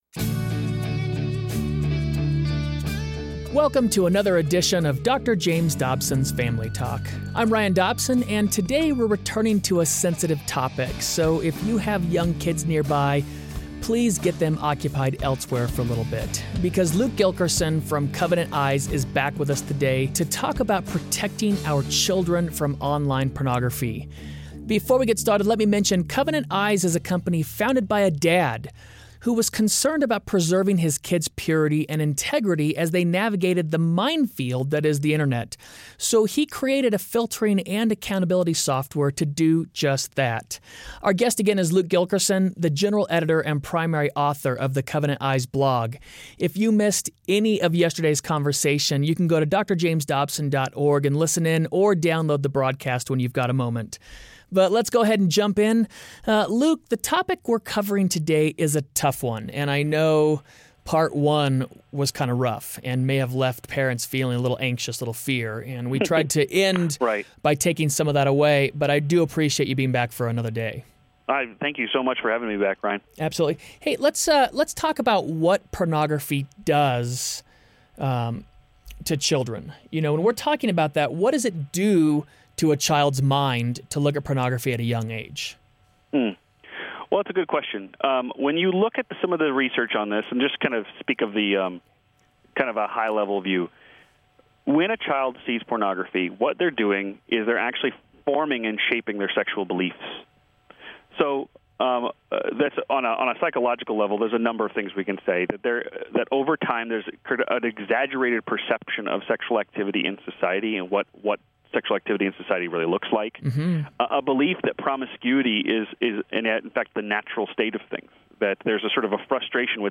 Get tips directly from an Internet safety expert on how to protect your family online. Whether it's establishing better communication with your kids, preparing them for possible "landmines" like pornography, or what to do if they've already stumbled across it, this is a conversation you can't miss! Hear this important discussion for every family.